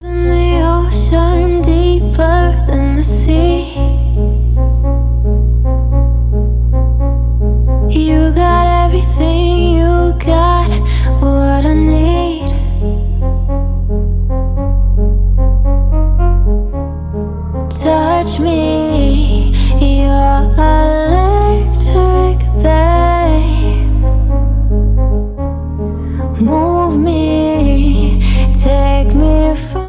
• Electronic